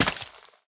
Minecraft Version Minecraft Version 1.21.5 Latest Release | Latest Snapshot 1.21.5 / assets / minecraft / sounds / block / suspicious_sand / break6.ogg Compare With Compare With Latest Release | Latest Snapshot